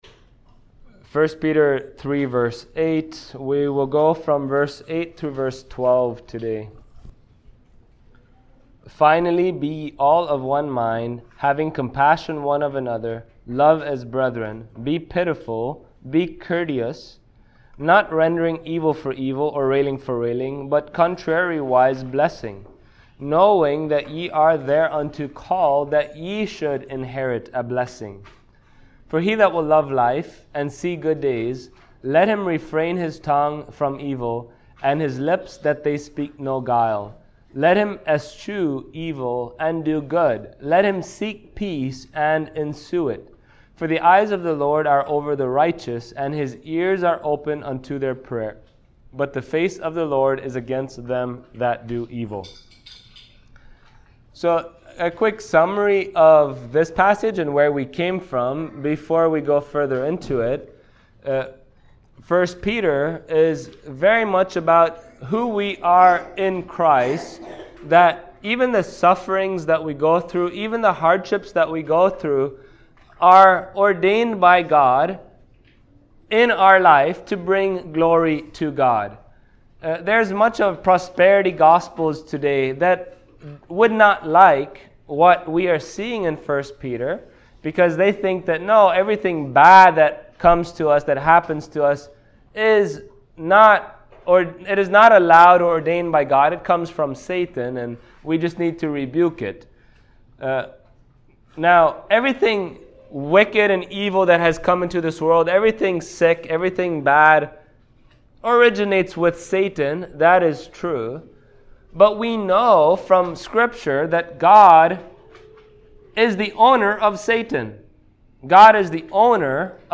1 Peter Passage: 1 Peter 3:8-12 Service Type: Sunday Morning Topics